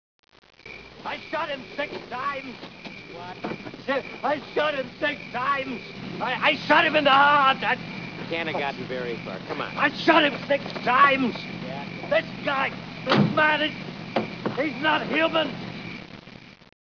Dr. Loomis talking about how Michael acted.